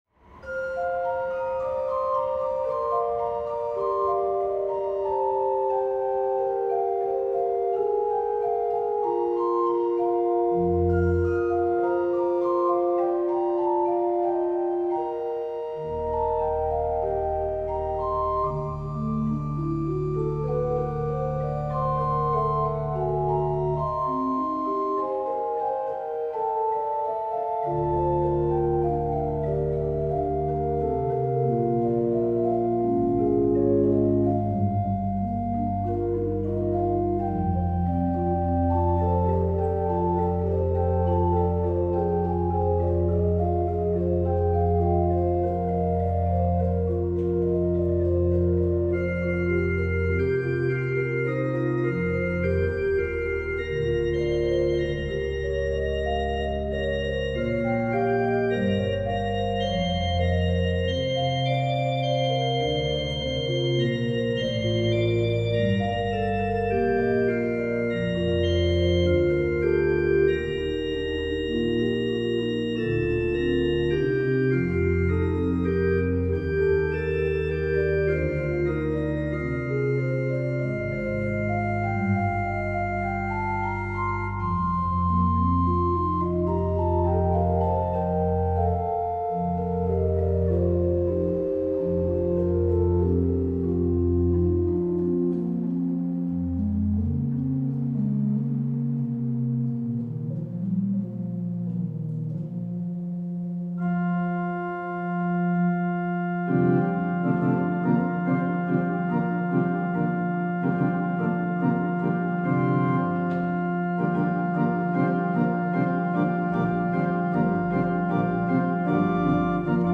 Hören Sie zur Vorfreude auf das hohe Pfingstfest eine Orgelimprovisation über den gregorianischen Chor Veni Creator Spiritus – Komm, Schöpfer Geist.
der Kirche St. Josef, Köpenick